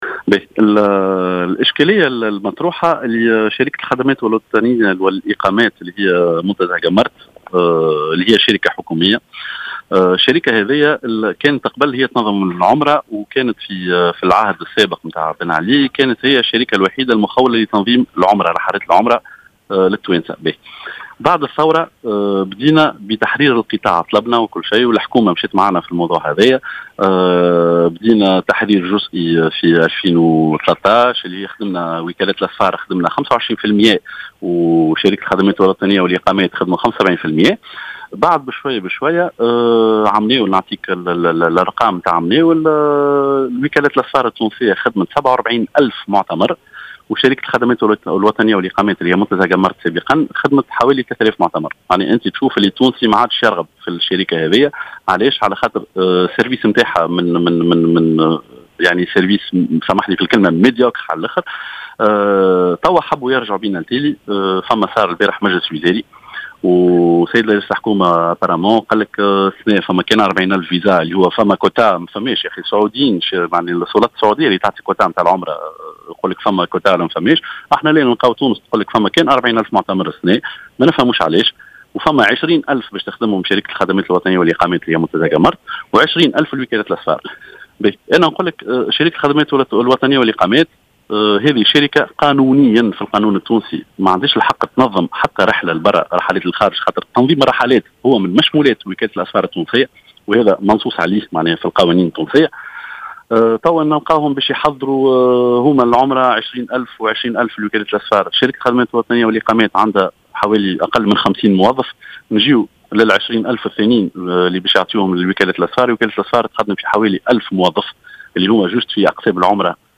في تصريح للجوهرة اف ام...